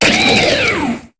Cri de Genesect dans Pokémon HOME.